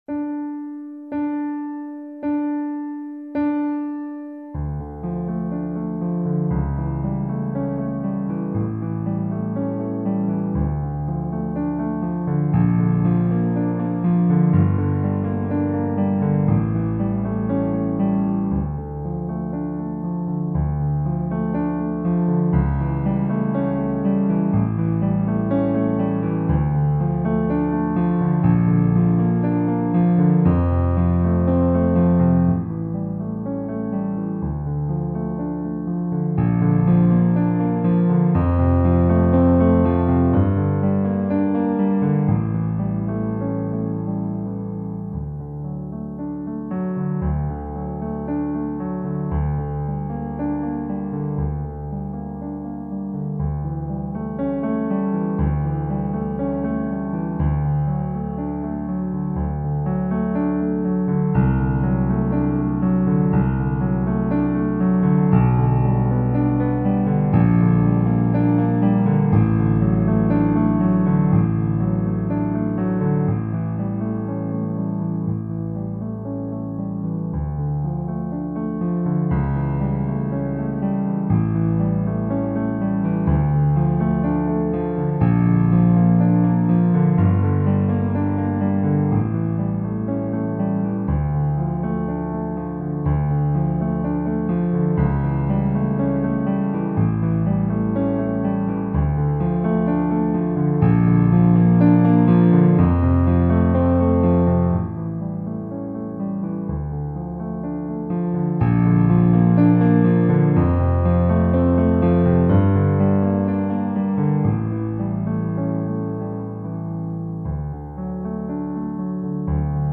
Der volle Klavierklang motiviert stark.